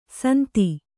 ♪ santi